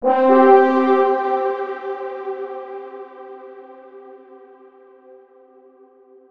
add missing short horn wav
horn call.wav